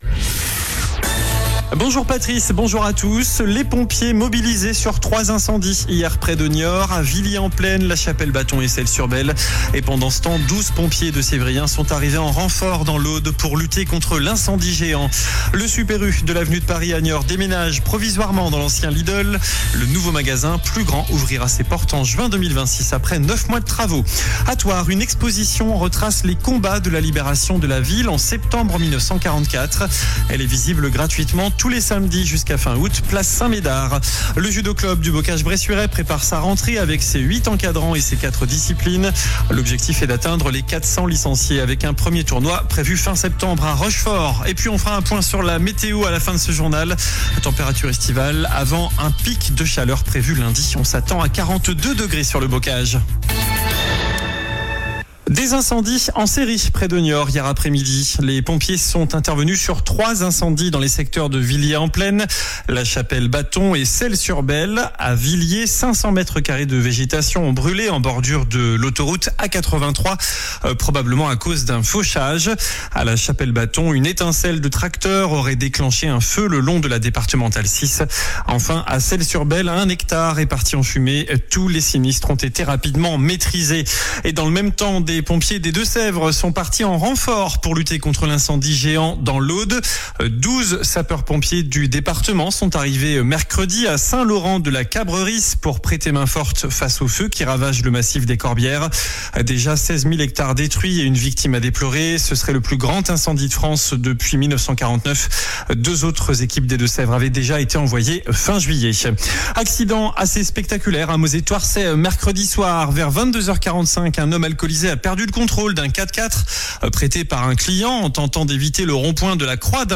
JOURNAL DU VENDREDI 08 AOÛT ( MIDI )